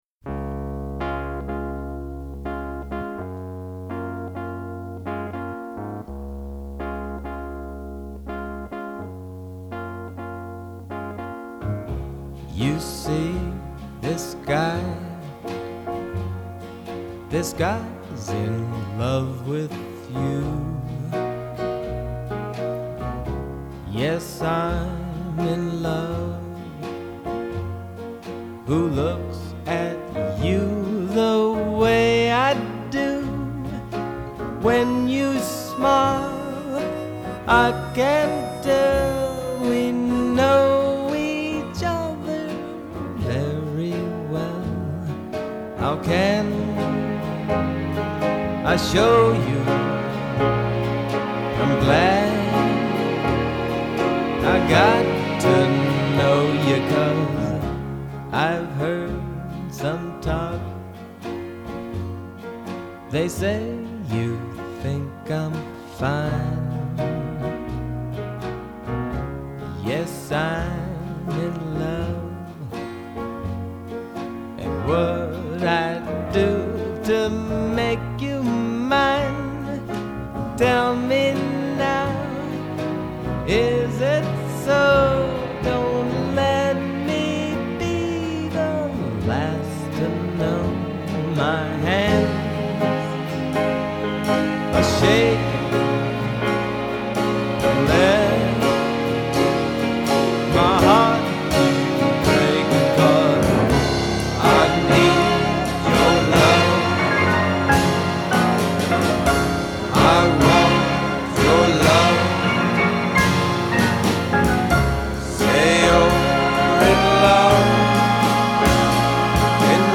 and with a vocal performance